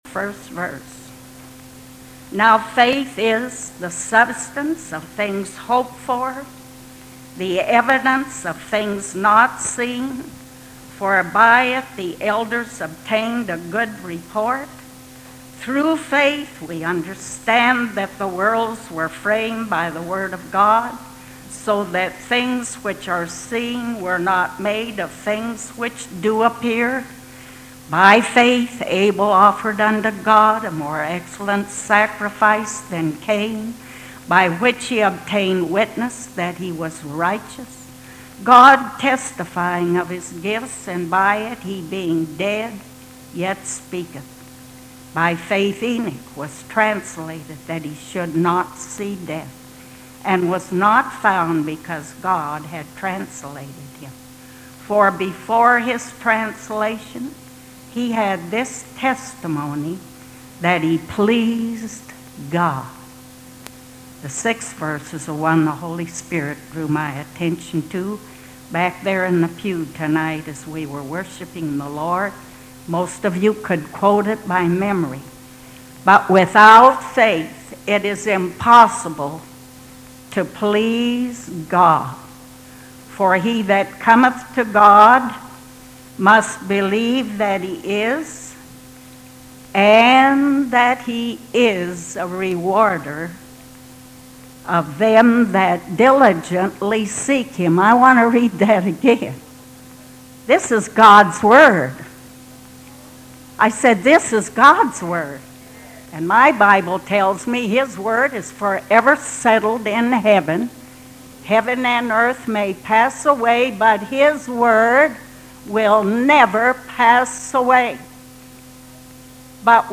Women Preachers